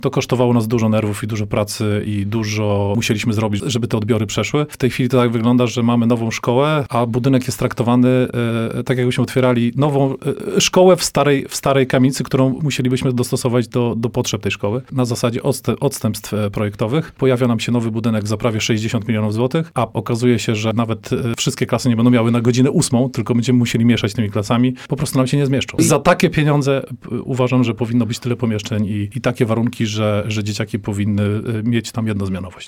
Popełniono ogromne błędy projektowe – mówił na naszej antenie burmistrz Ostrowi Mazowieckiej Hubert Betlejewski o otwartym niedawno nowym budynku szkoły podstawowej nr 1 przy ulicy Partyzantów.